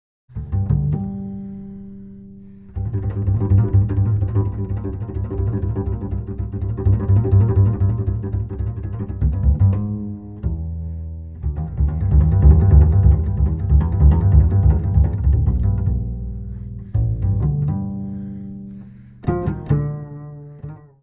: stereo; 12 cm
Music Category/Genre:  Jazz / Blues